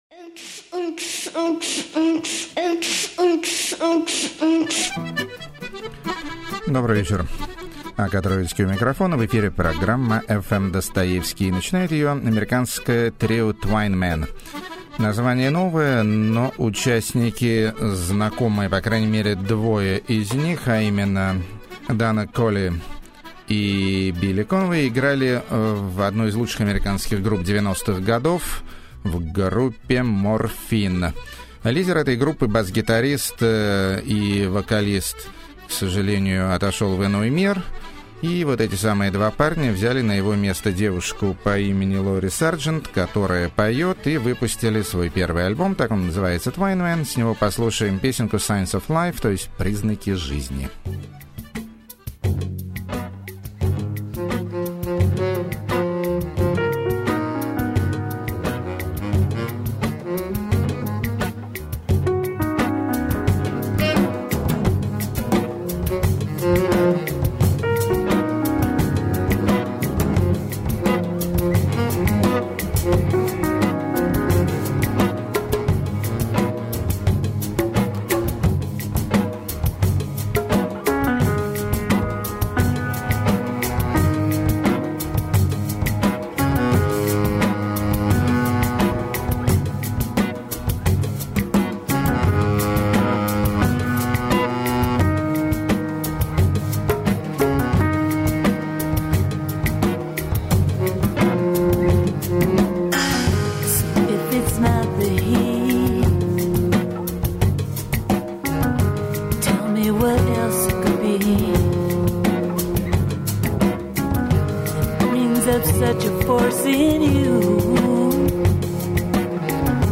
Ethno-dub
Eurobeauty
Techno-swing??
Gypceltic
Klezmftwerk
Electrogotica